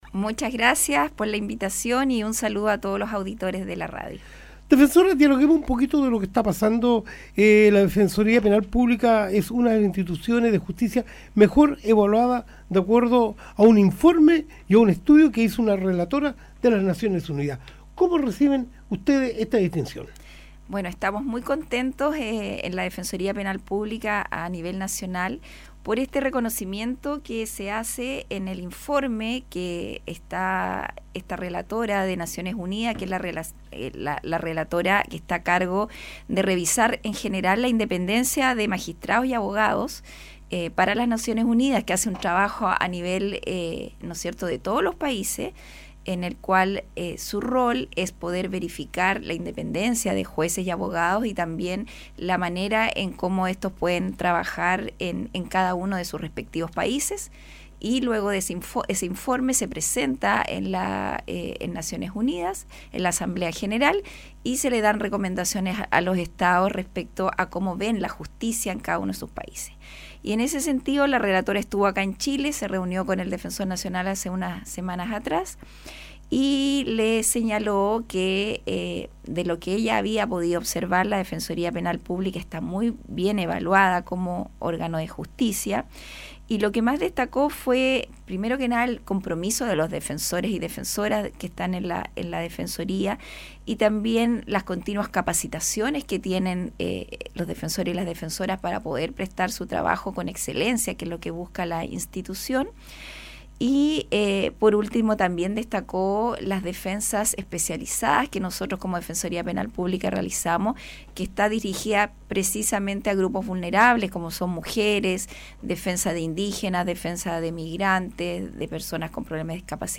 Defensora Regional de Coquimbo en entrevista radial:
Inés Rojas explicó, en radio "San Bartolomé", la misión de la Defensoría Penal Pública y en qué consiste el "Proyecto Inocentes".